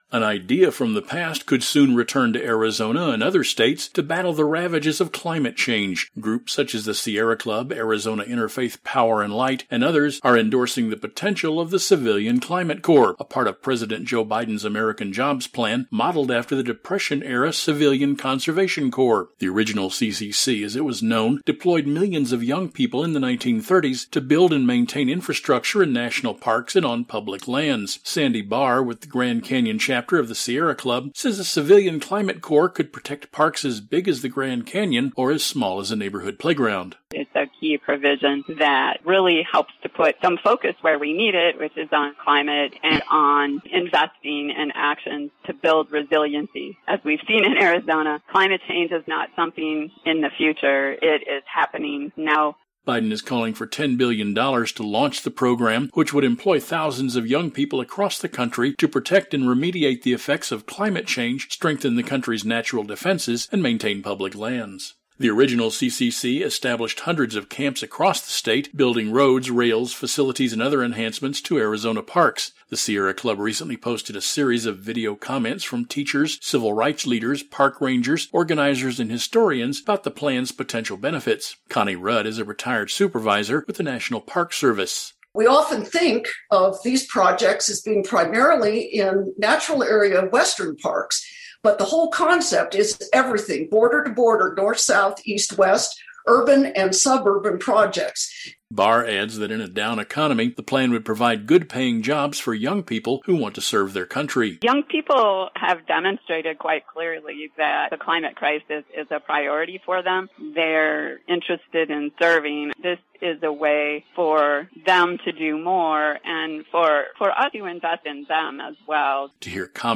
Protect Earth News Radio Reports: June, 2021